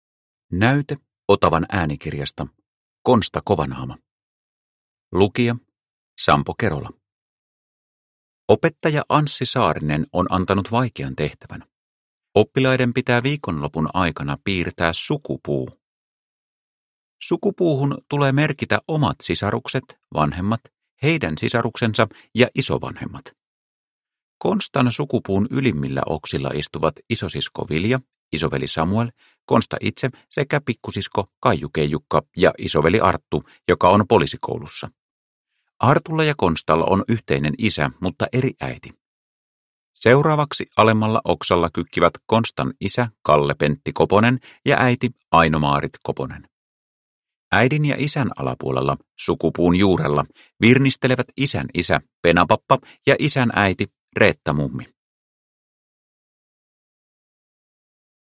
Konsta, kovanaama – Ljudbok – Laddas ner